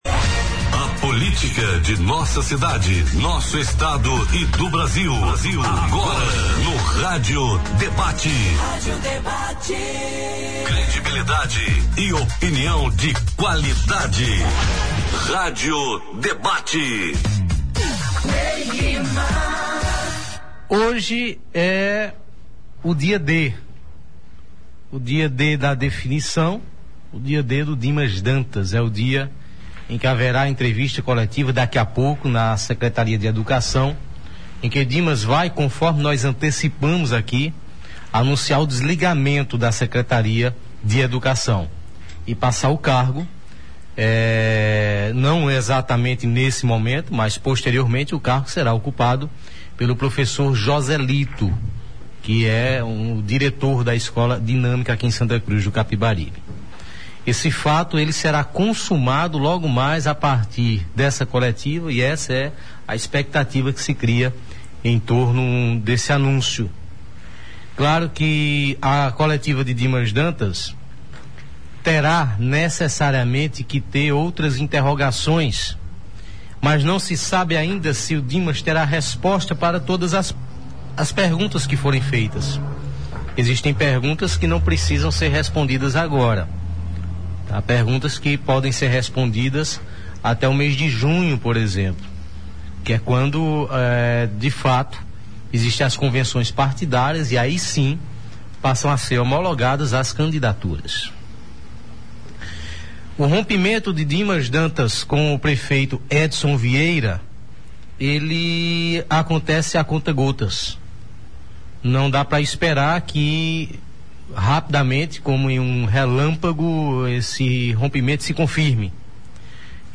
Aconteceu durante o programa, a transmissão ao vivo da coletiva do vice-prefeito Dimas Dantas (PP), que anunciou o desligamento da secretaria de Educação de Santa Cruz do Capibaribe, cargo que acumulava desde junho de 2013.